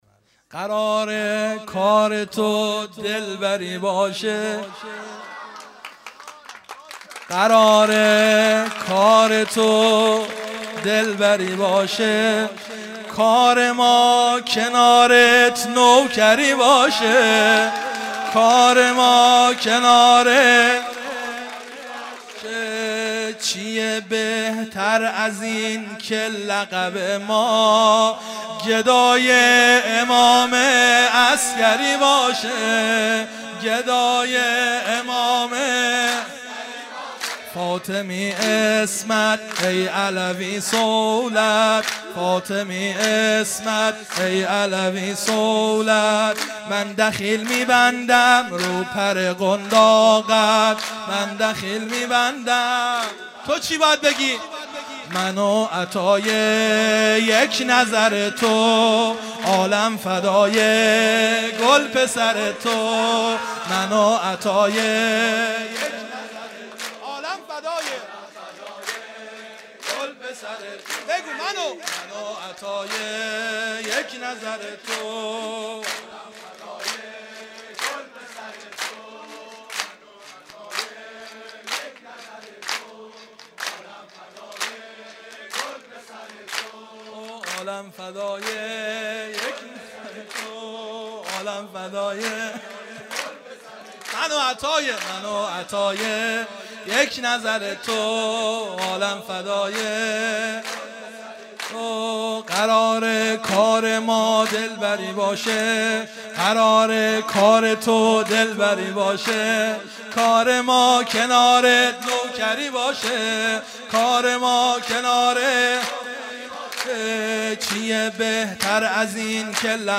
مراسم 19 بهمن ماه 1392 - میلاد امام حسن عسکری (ع)